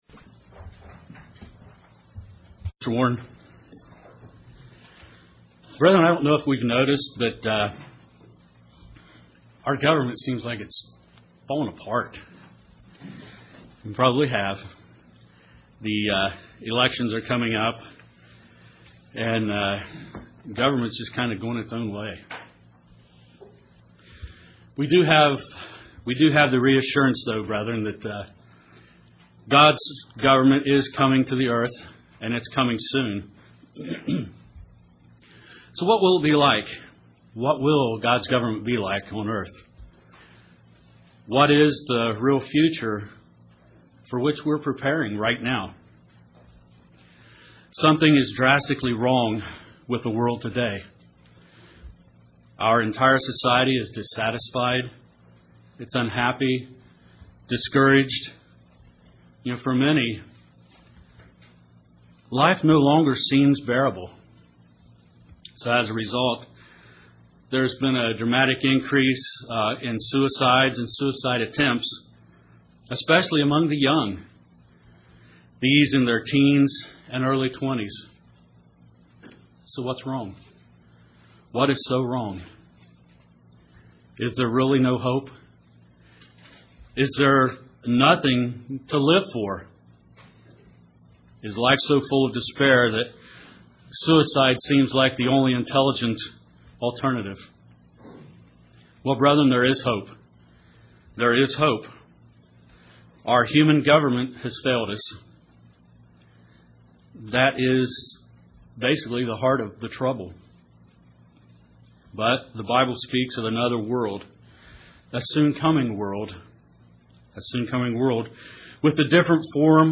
Sermons
Given in Paintsville, KY